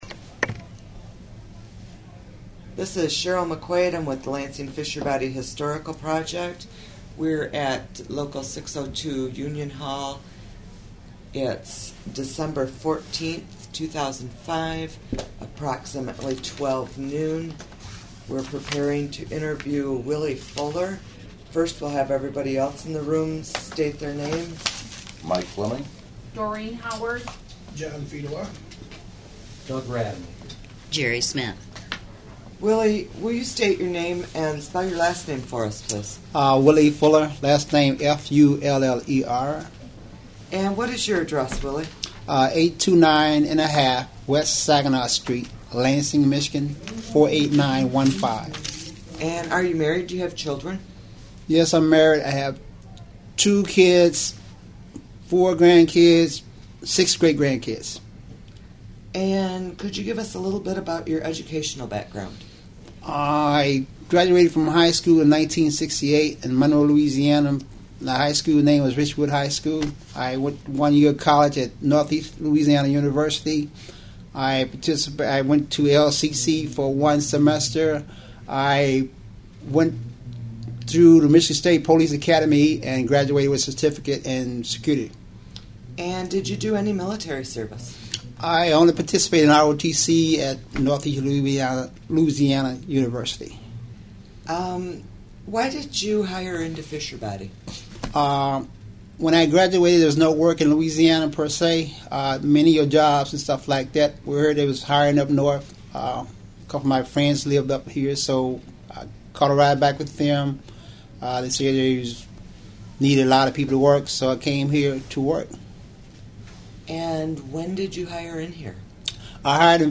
United Auto Workers Local 602/General Motors Oral History Project